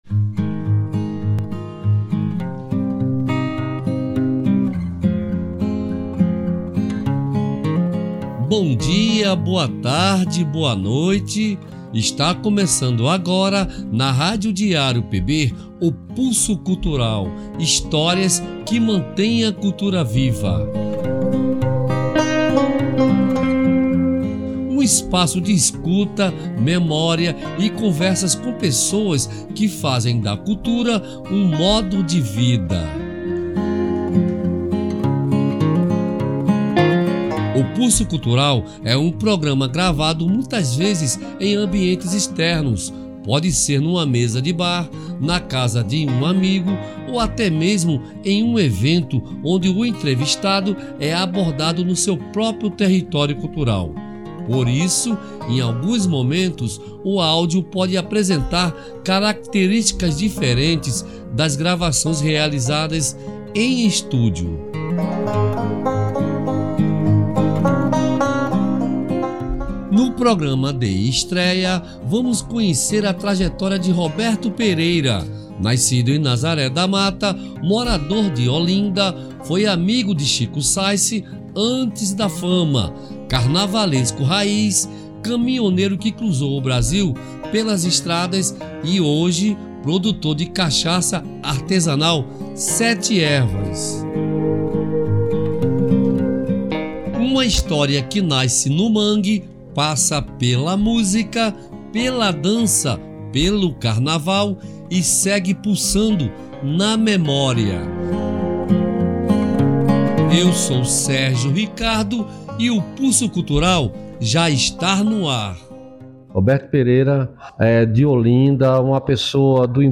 O Pulso Cultural é um programa gravado, muitas vezes, em ambientes externos, pode ser numa mesa de bar, na casa de um amigo ou até mesmo em um evento, onde o entrevistado é abordado no seu próprio território cultural. Por isso, em alguns momentos, o áudio pode apresentar características diferentes das gravações realizadas em estúdio.